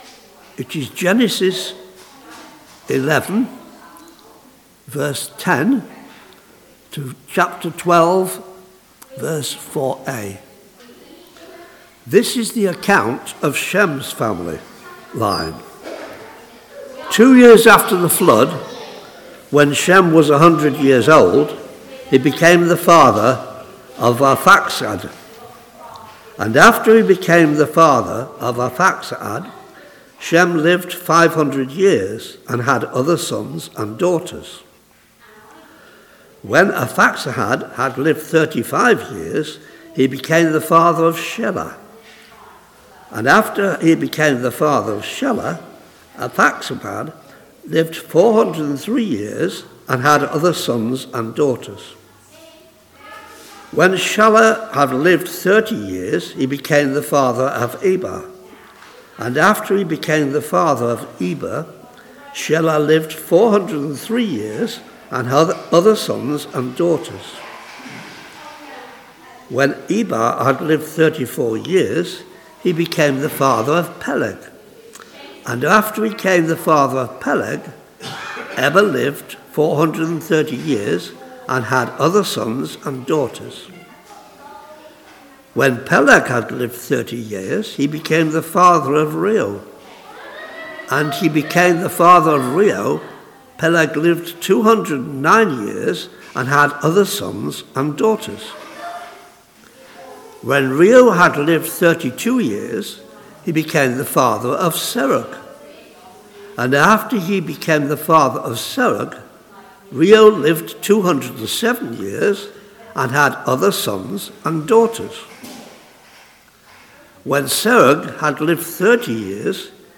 Media Library We record sermons from our Morning Prayer, Holy Communion and Evening services, which are available to stream or download below.
Passage: Genesis 11:10-12:3 Series: The Gospel for the Nations Theme: Sermon Search